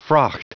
Prononciation du mot fraught en anglais (fichier audio)
Prononciation du mot : fraught